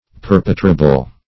Perpetrable \Per"pe*tra"ble\